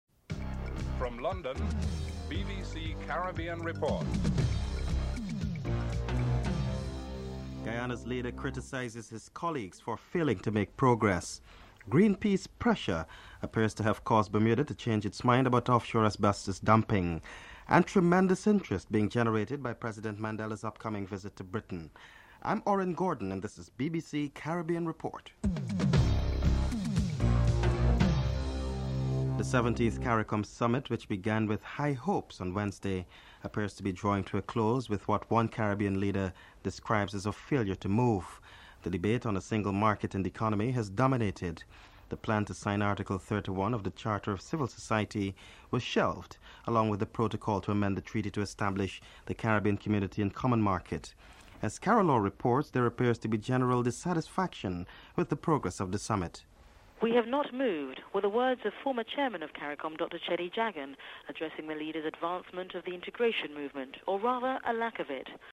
1. Headlines (00:00-00:28)